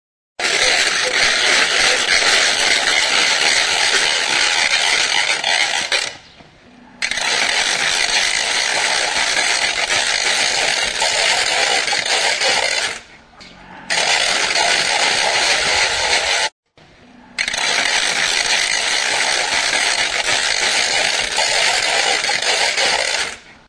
JOALDIA. Amara Berri eskolako haurrak. Oiartzun, 2004.
CARRACA
Idiophones -> Struck -> Ratchet system
Idiophones -> Scraped